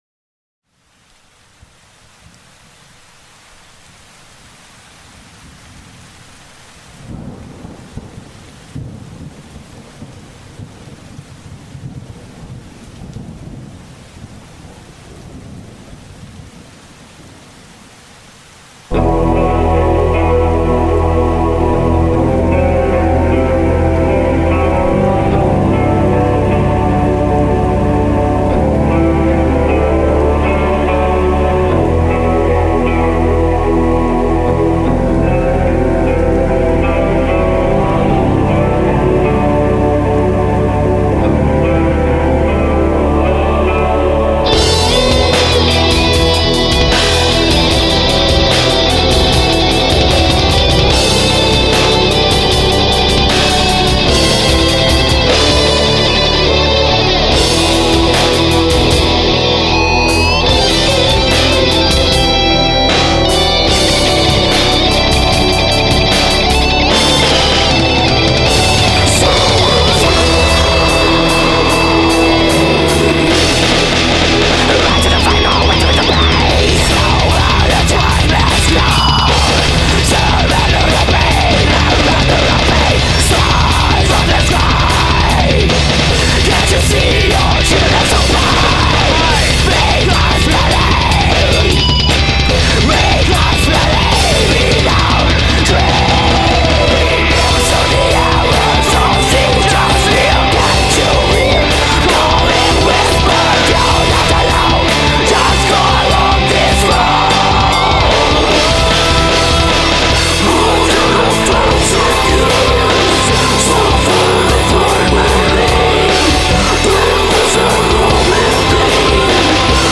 dobok